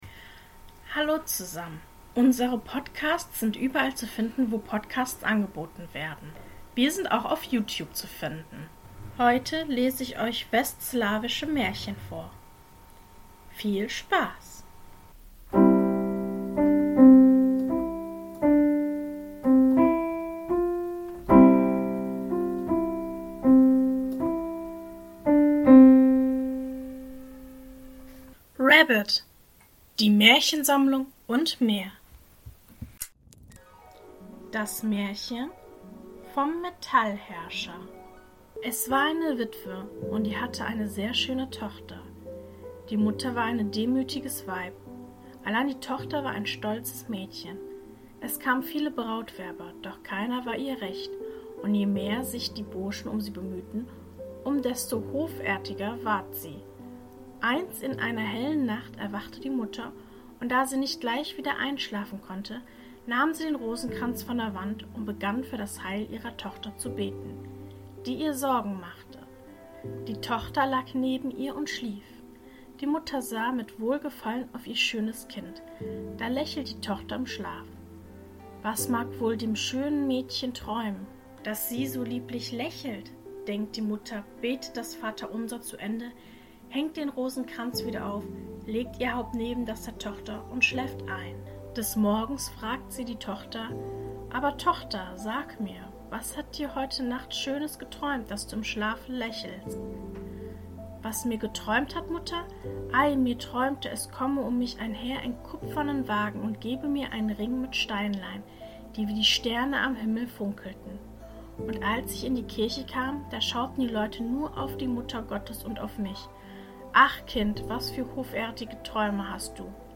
In diesem Podcast erzähle ich Euch verschiedene Märchen und möchte Euch einladen zu träumen und die Zeit gemeinsam zu genießen. Die Märchen werden aus aller Welt sein und sollen Euch verleiten, dem Alltag etwas zu entfliehen.